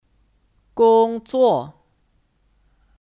工作 (Gōngzuò 工作)